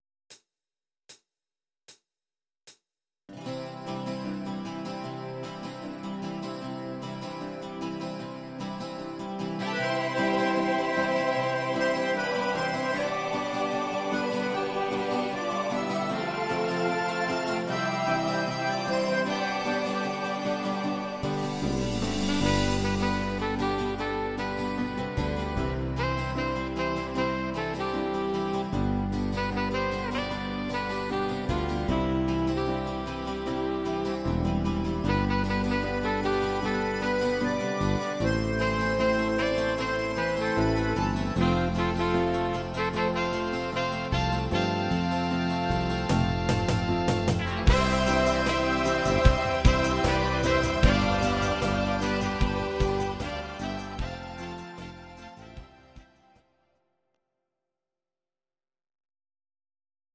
These are MP3 versions of our MIDI file catalogue.
Your-Mix: Volkstï¿½mlich (1262)